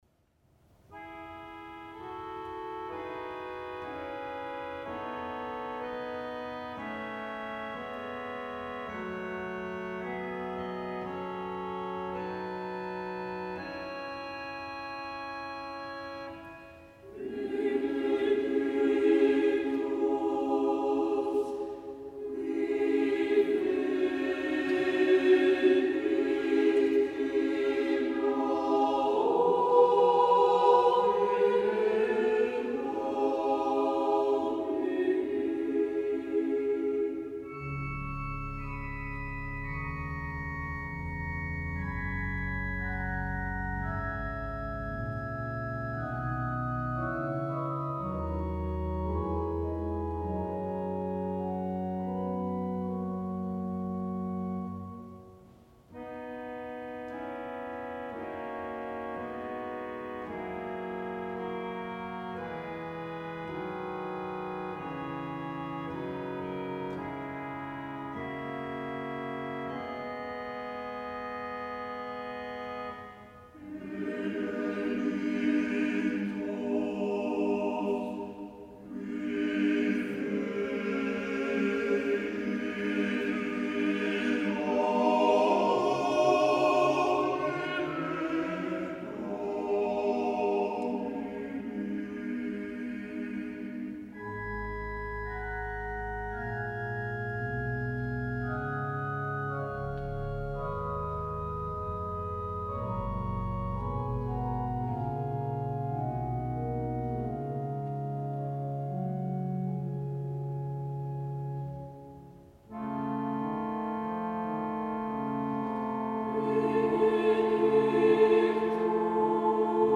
harmonium
kerkorgel